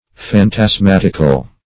Search Result for " phantasmatical" : The Collaborative International Dictionary of English v.0.48: Phantasmatical \Phan`tas*mat"ic*al\, a. [L. phantasmaticus.]